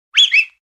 Whistle1.wav